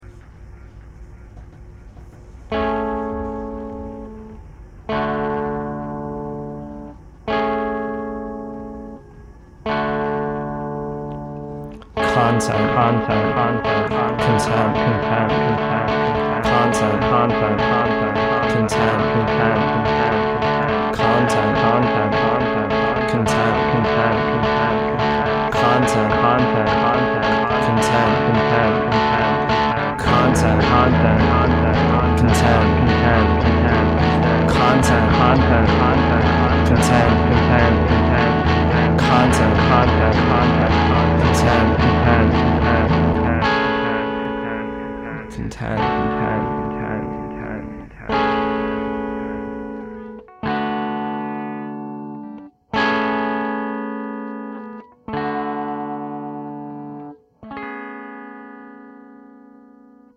the winter of my senior year of college, i bought a two input line 6 audio recording interface and an sm-57 microphone.
the lyrics of which are just the word “content” pronounced two different ways over and over again
layered downstroke electric guitars the use of EQ’d down guitar as “bass” blown-out sound (both aesthetically and also unintentionally) faux-analog (all effects are always digital)